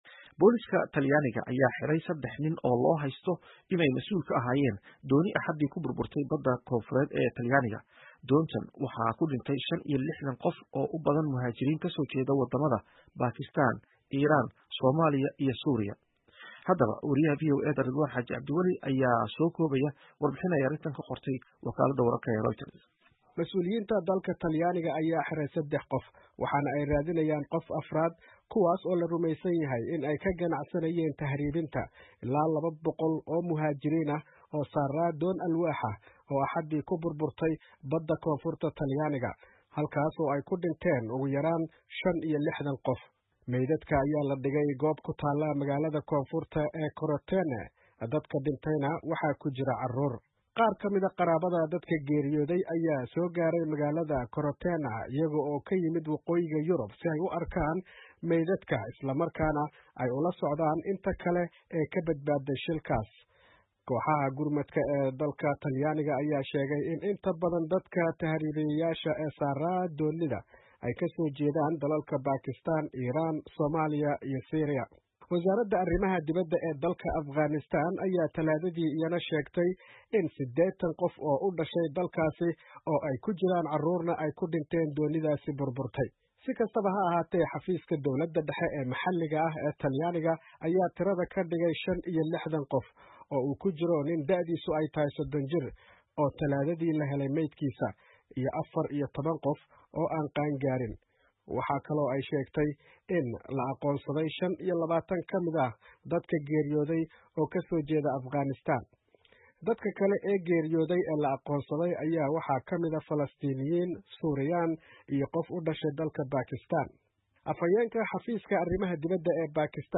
ayaa soo koobaya warbixin ay qortay wakaaladda wararka ee Reuters.